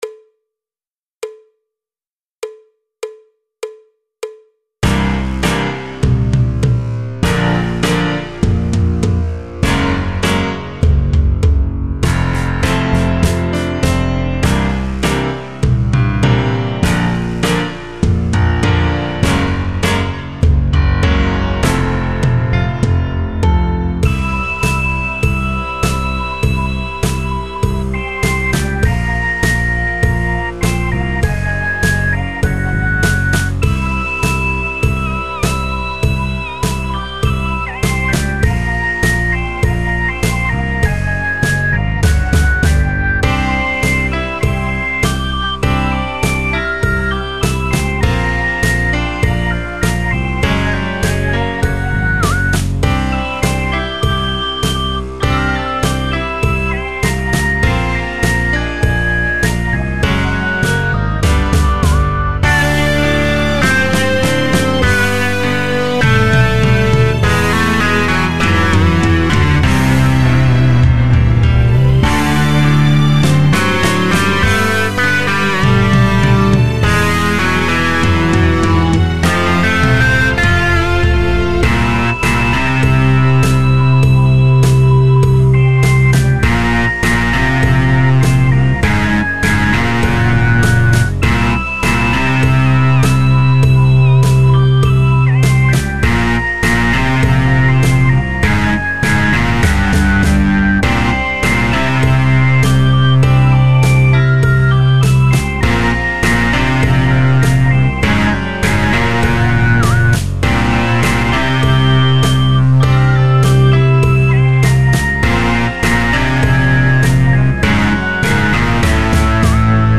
Batterie Solo